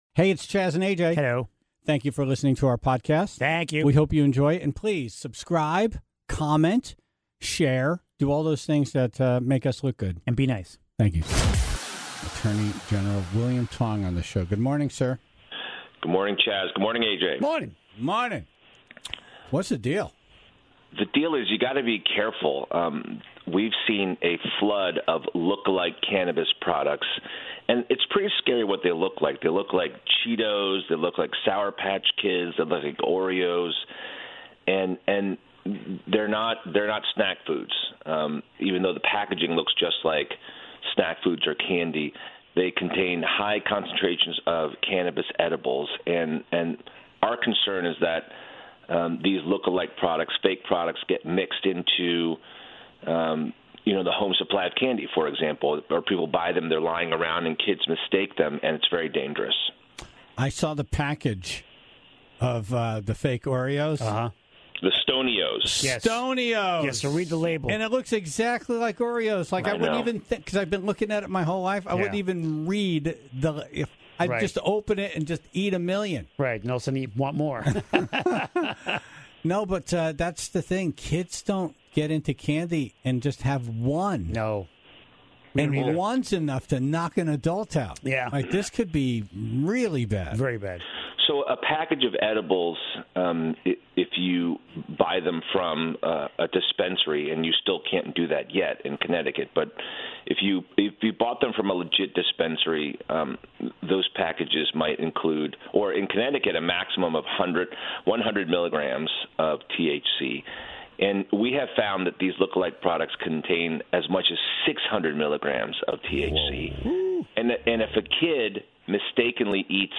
Attorney General William Tong was on to issue a warning to parents ahead of Halloween, and admitted he needs to buy more candy for Trick-or-Treaters because he's already opened the bags of candy he bought.